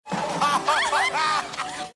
Play Risa Viejo De La Tele Robocop - SoundBoardGuy
risa-viejo-de-la-tele-robocop.mp3